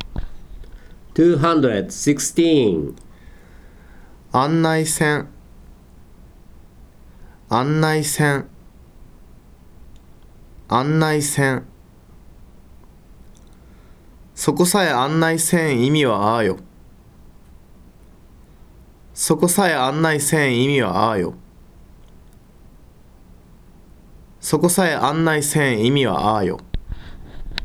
If you click the word in a cell in the table, then you can hear the `negative' form of the verb and a sentence containing the `non-past' form as the verb of the adnominal clause in Saga western dialect.
216 aNnai seN.WAV